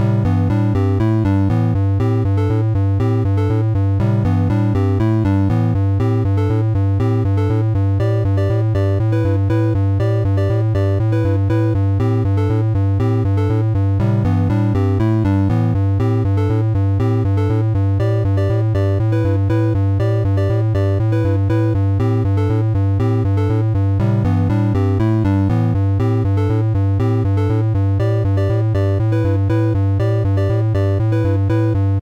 A short battle music loop.